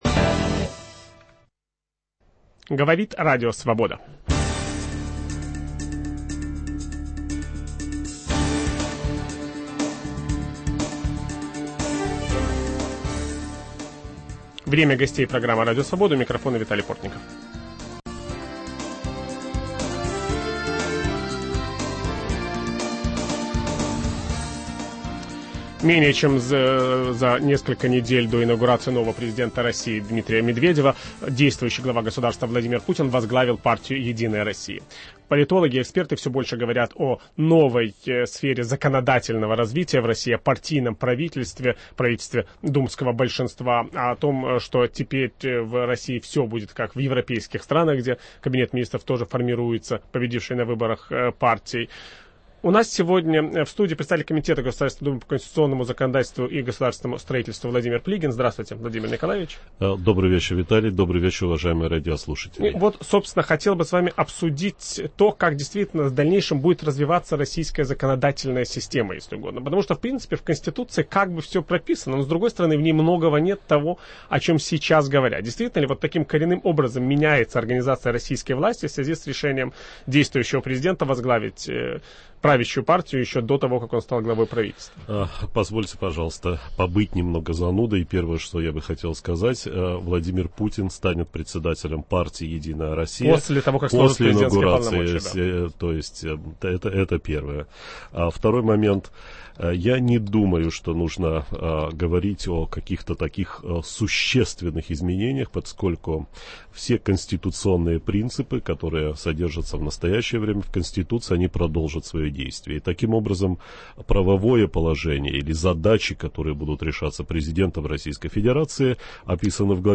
На вопросы Виталия Портникова отвечает глава думского комитета по конституционному законодательству и госстроительству Владимир Плигин